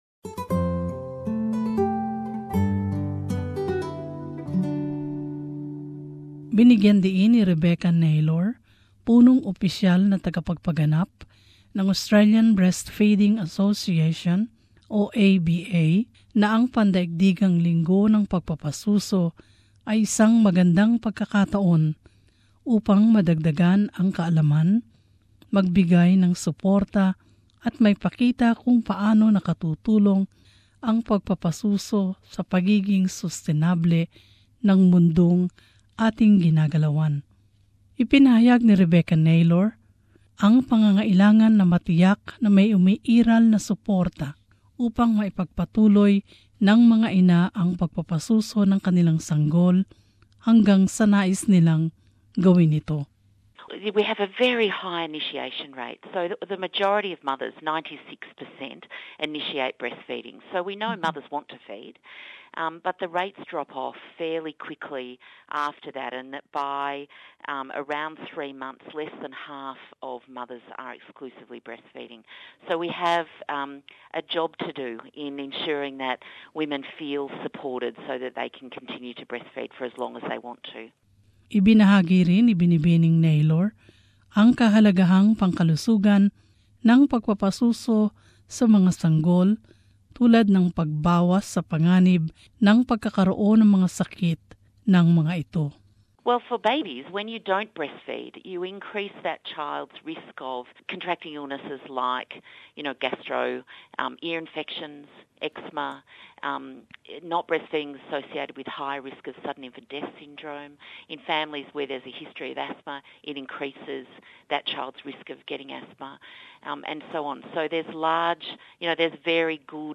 In this feature, we used some grabs from that interview as well as the voices of some Filipino women who narrated their experience with breastfeeding.